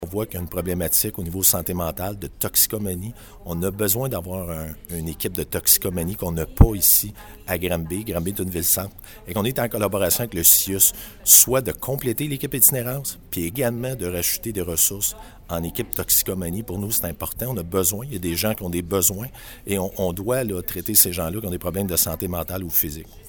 Comme l’indique le directeur du Service de police de Granby, Bruno Grondin, les besoins en ressources sont criants : Granby, augmentation securite centre-ville, 19.11.24_Grondin, clip Par ailleurs, le SPG a dû procéder à une dizaine de démantèlements de campements d’itinérants.